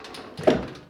crateClose.ogg